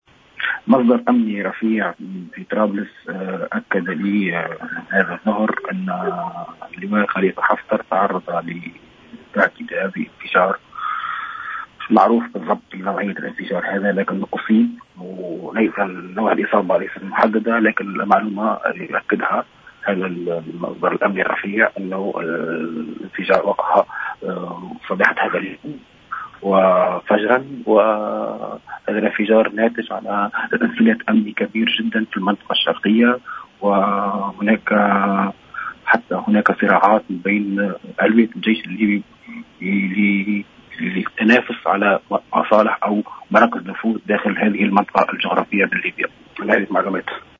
une intervention sur les ondes de Jawhara FM